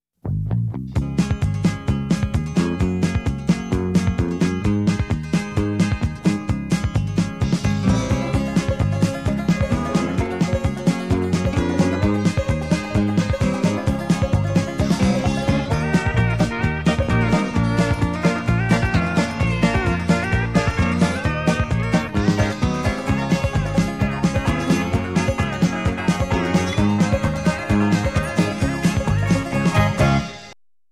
6. Ритмичная музыка